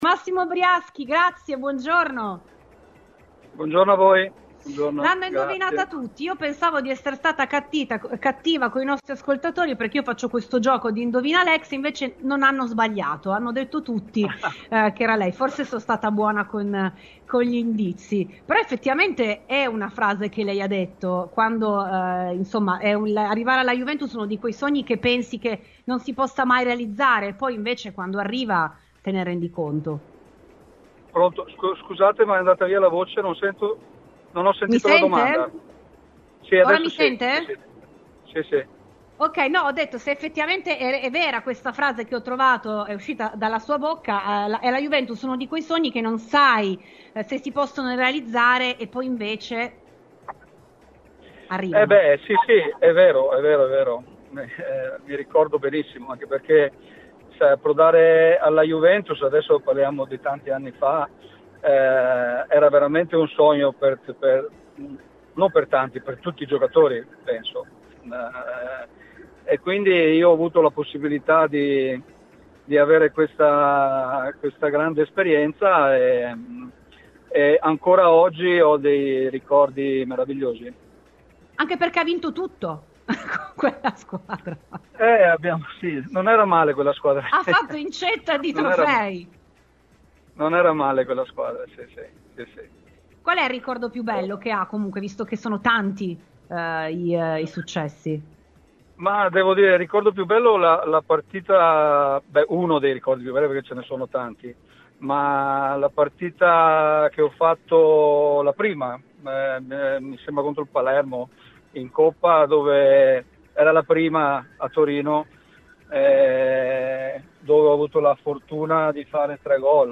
In vista della sfida di domani tra Juventus e Genoa, lo abbiamo intervistato su Radiobianconera durante la trasmissione RBN Cafè.